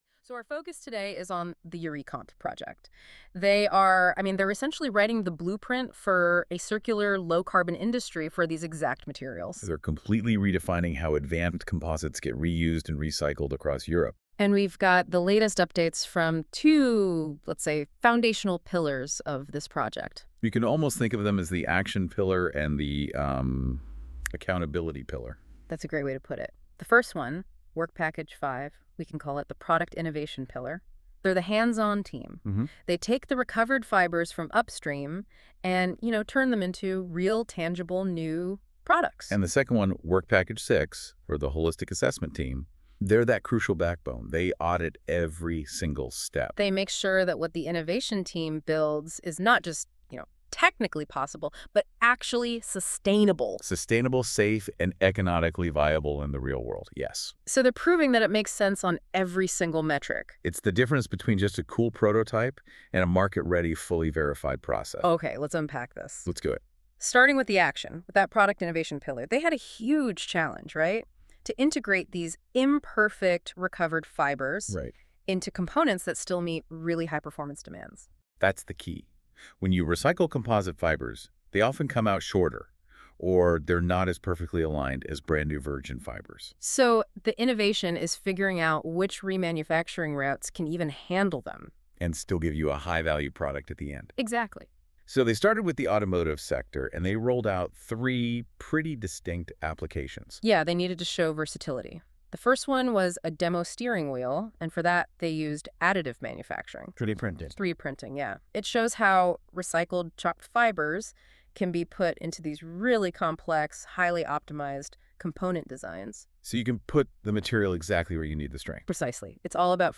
Using AI technology, we have created an Audio Brief —a podcast-style summary of the newsletter's key takeaways.